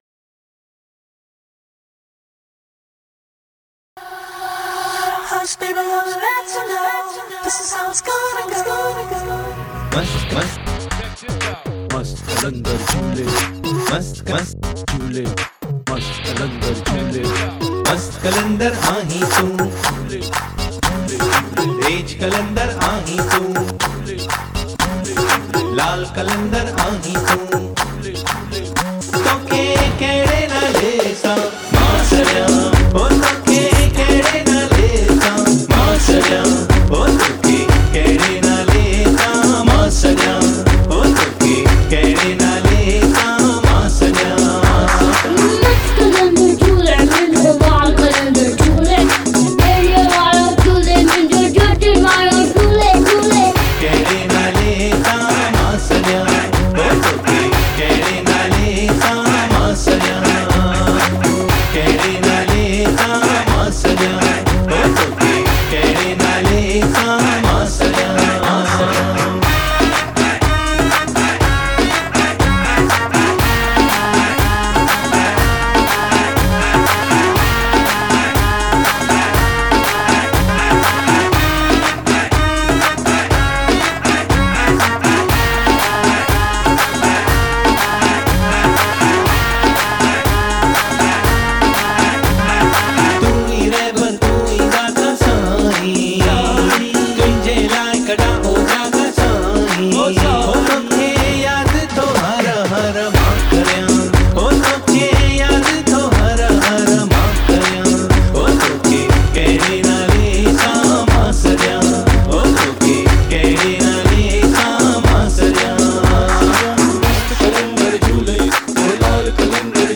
Rap Singer
Backing Vocals